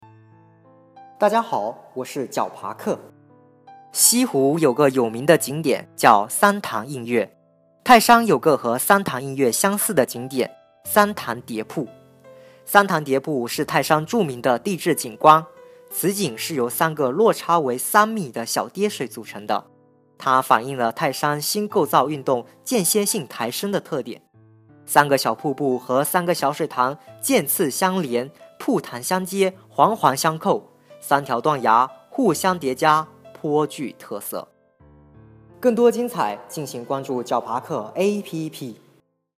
三潭叠瀑----- 石破天惊 解说词: 在泰山地区，由于新构造运动间歇性的抬升，发育了众多三级型的微型地貌。